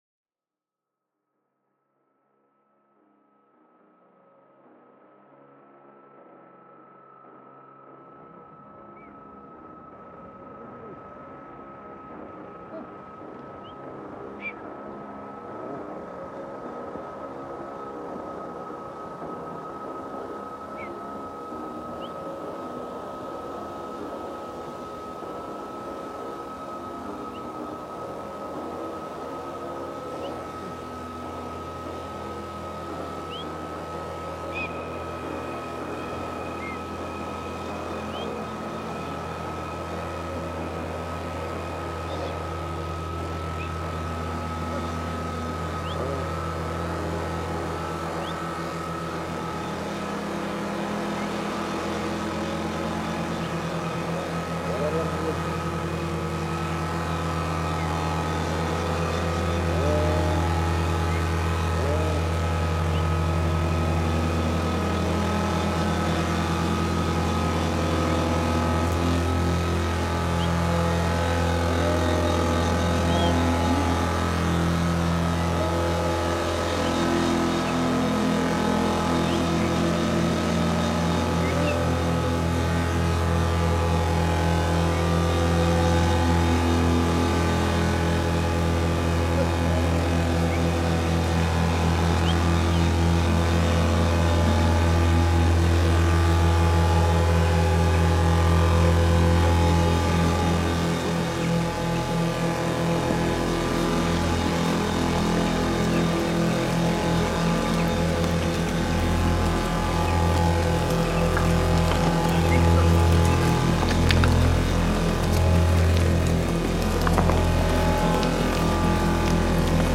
Xinjiang nomad recording reimagined